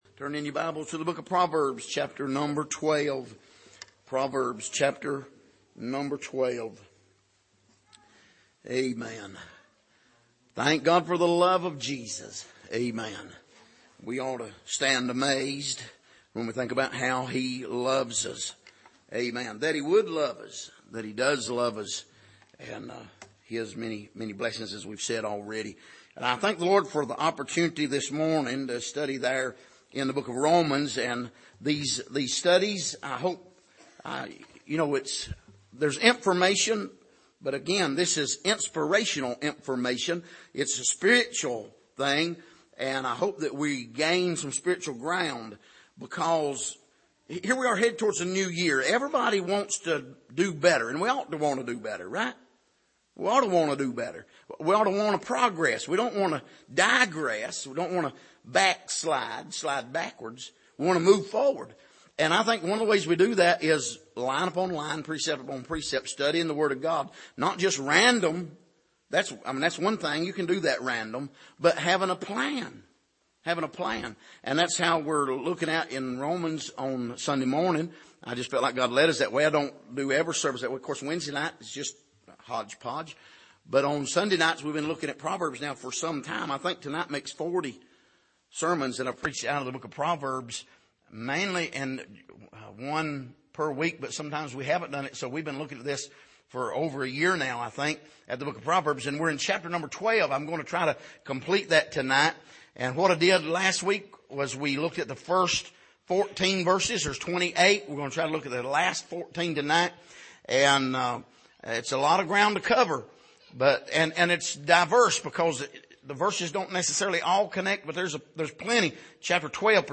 Passage: Proverbs 12:15-28 Service: Sunday Evening